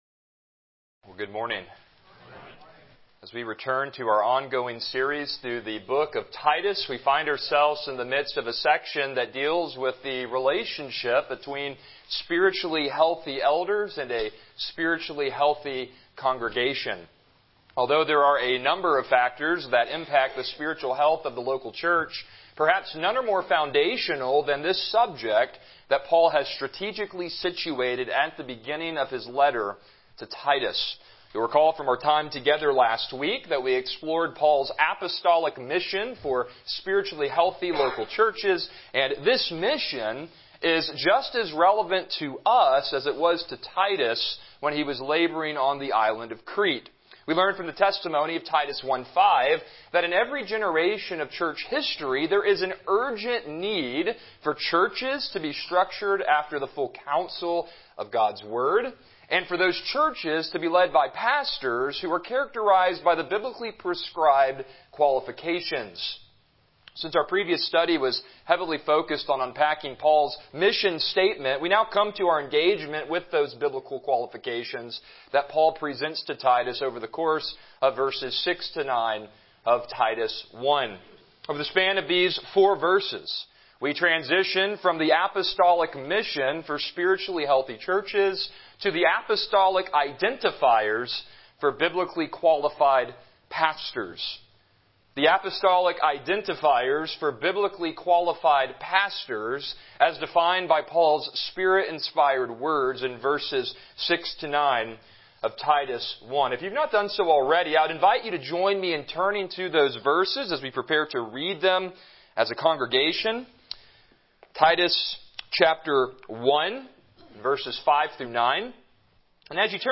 Passage: Titus 1:6-8 Service Type: Morning Worship